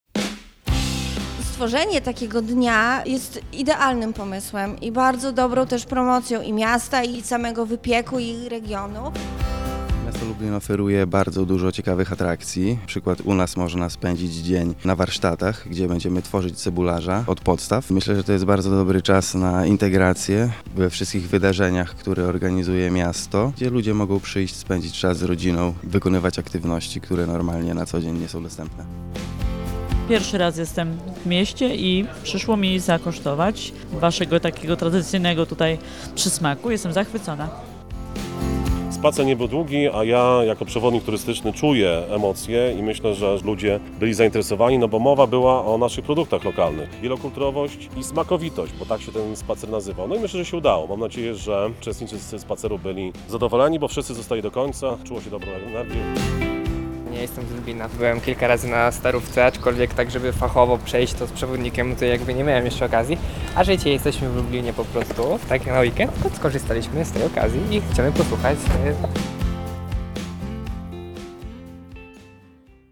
Więcej o wydarzeniu opowiadają nam jego uczestnicy.